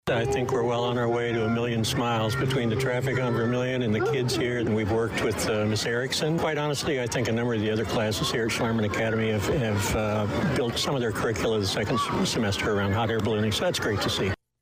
It was outside at Voorhees and North Vermilion, with the big, inflated balloon on the ground taking up much of the parking lot.